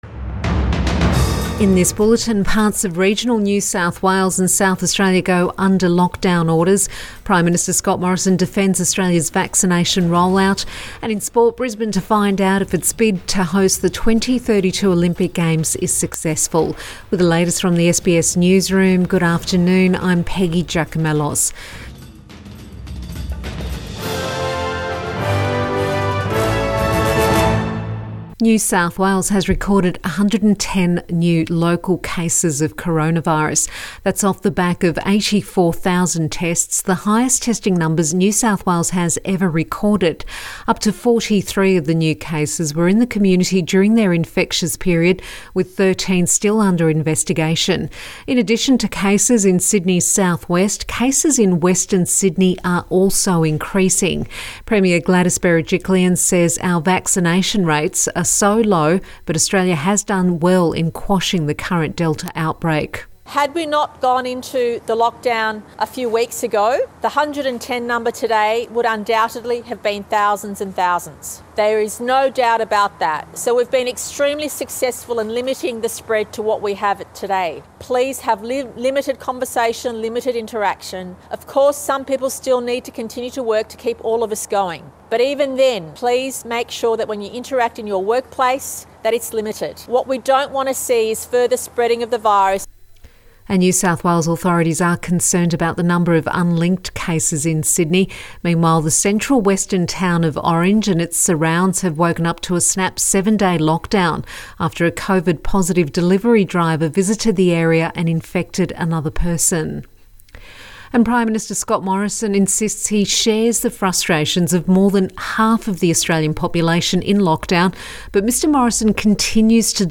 Midday bulletin 21 July 2021